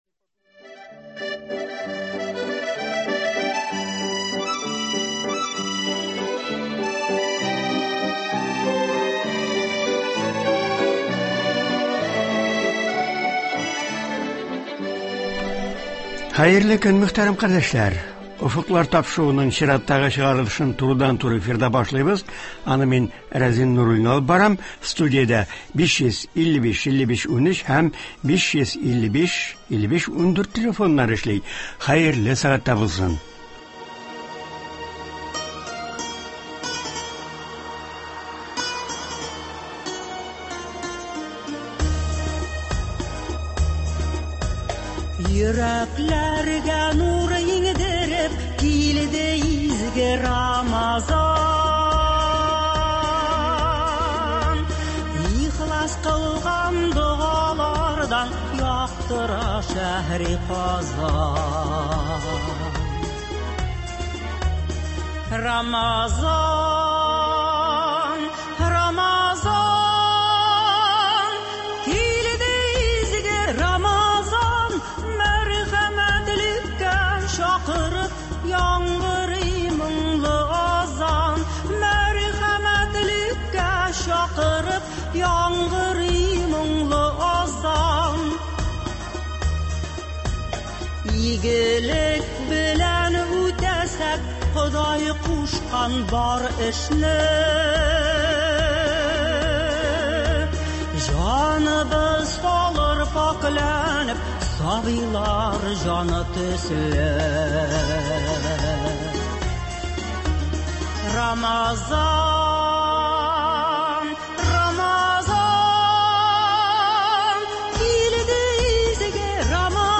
Мөселман дөньясында изге Рамазан ае дәвам итә. Бу көннәрдә республикабызда дин өлкәсендә нинди чаралар үткәрелә? Болар хакында турыдан-туры эфирда